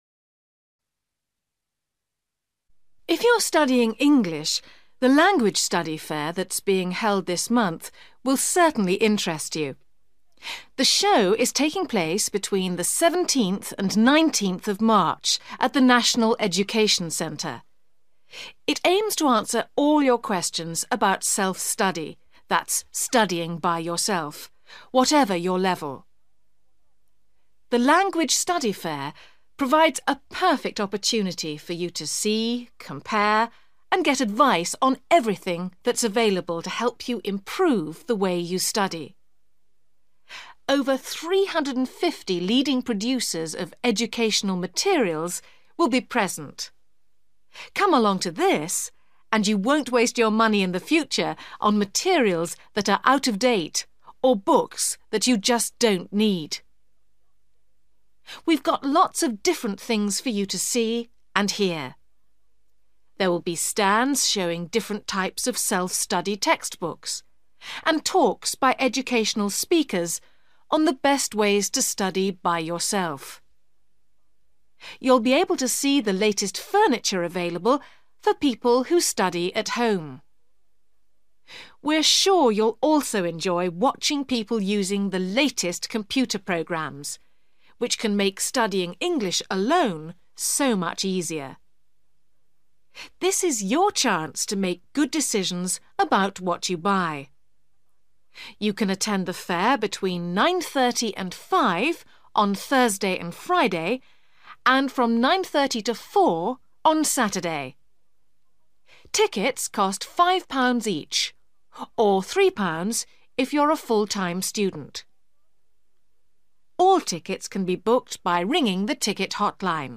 You will hear someone talking on the radio about a Language Study Fair.